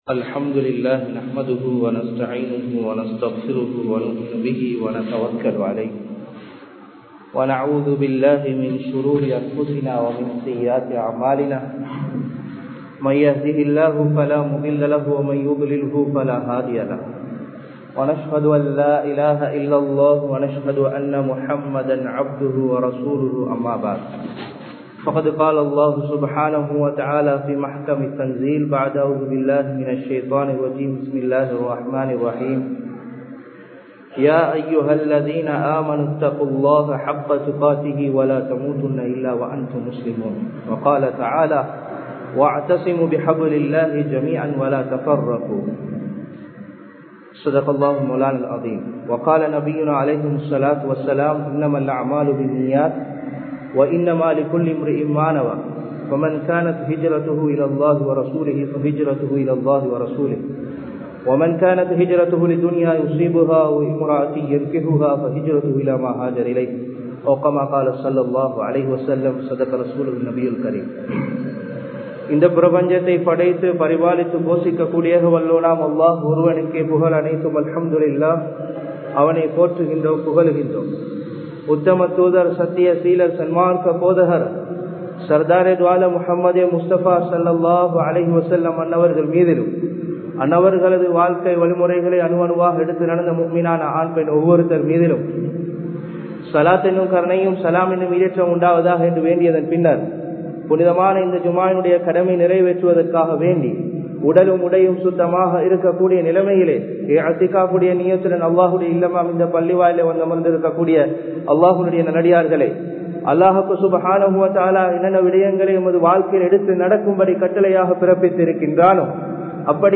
மஸ்ஜிதின் சிறப்புகள் (Merits of the Masjidhs) | Audio Bayans | All Ceylon Muslim Youth Community | Addalaichenai
7th Mile Post Jumua Masjith